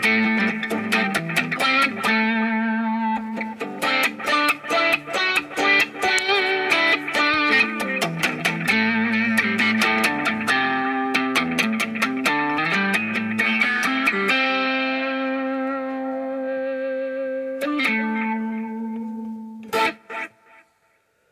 Habe mal eben das Fender Studio auf dem Smartphone installiert und einen kurzen Soundtest aufgenommen verwendet habe ich dafür das Preset Nr. 09 Solo Wah damit läßt sich arbeiten your_browser_is_not_able_to_play_this_audio